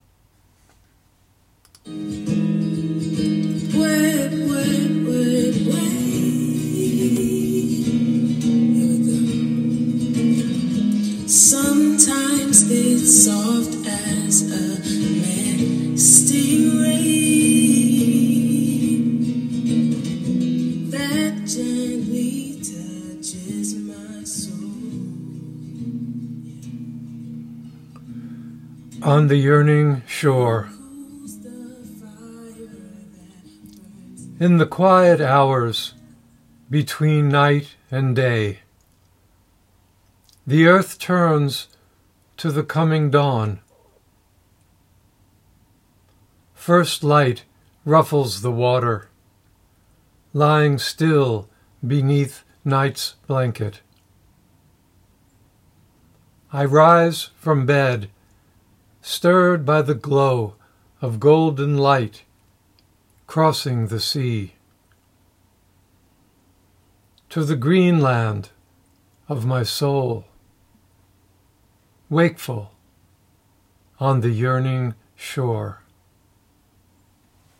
Reading of “On The Yearning Shore” with music by Sunday Service Choir.